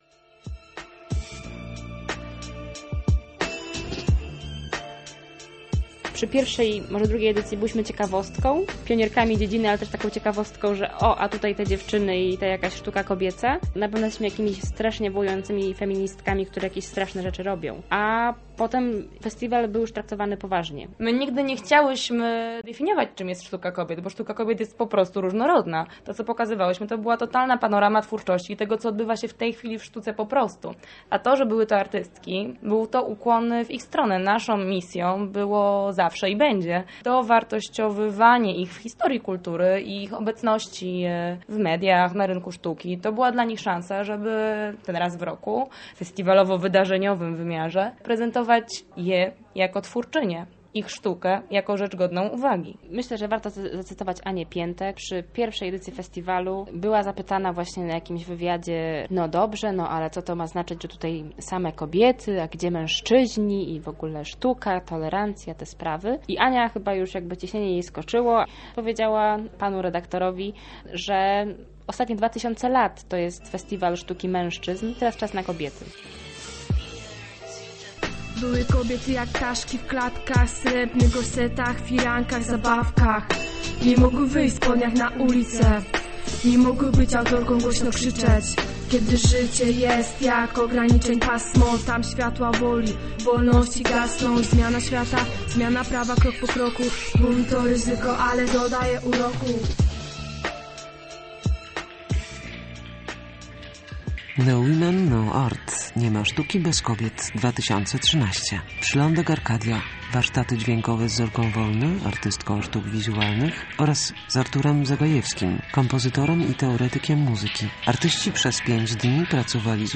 "Podejrzane" - reportaż